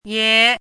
怎么读
ye2.mp3